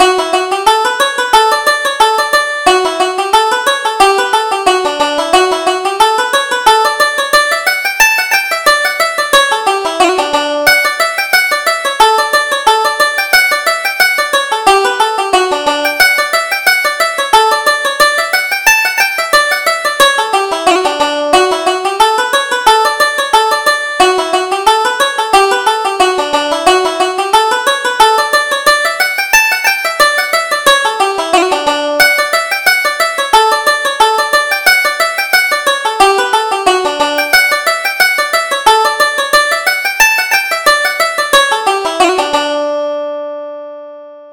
Reel: My Love Is in America